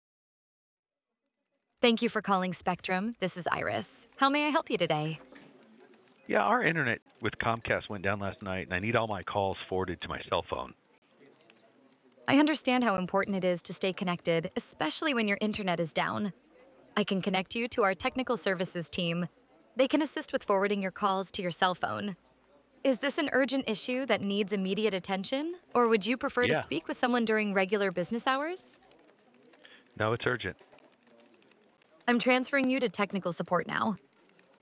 Hear how AI answers real calls
• Natural, conversational voice